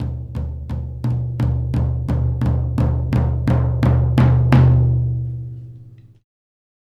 Track 02 - Toms 02.wav